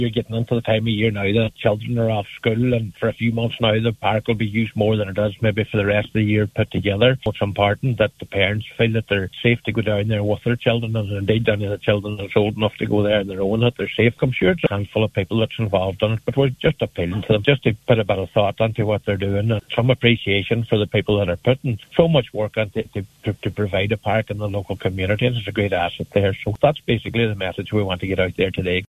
Local Councillor Michael McBride says there’s been a huge community effort to develop the facility, and those behind these incidents need to consider the impact their actions are having…………